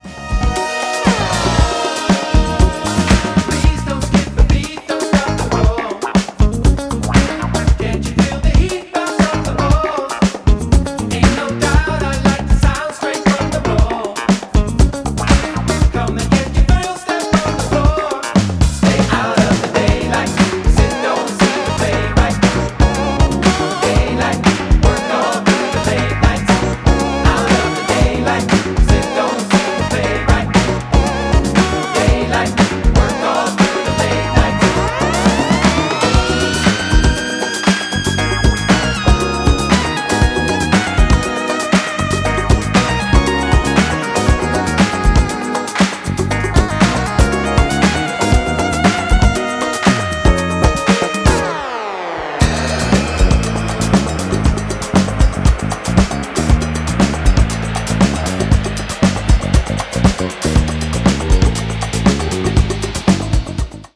and bolstered throughout by a dedicated brass section.